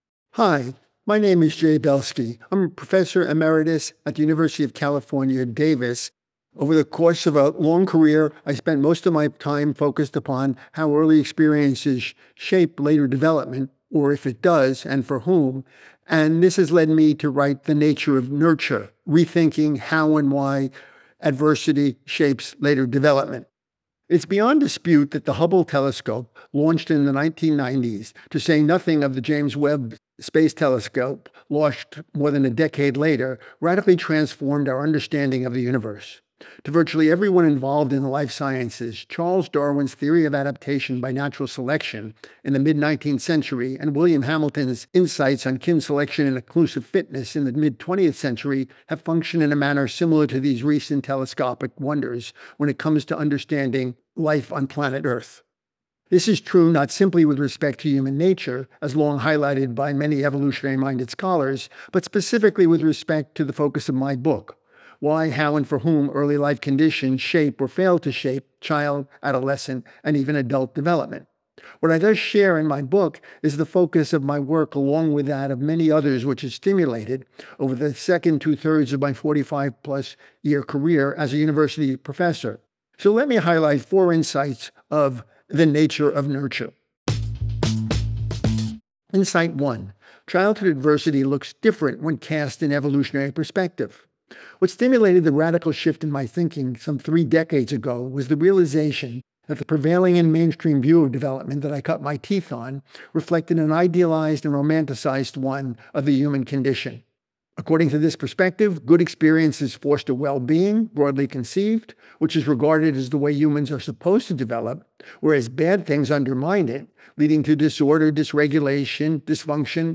Listen to the audio version of this Book Bite—read by Jay himself—below, or in the Next Big Idea App.